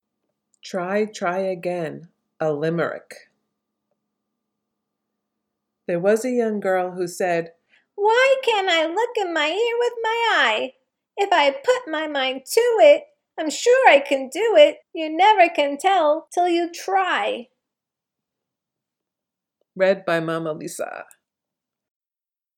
A limerick...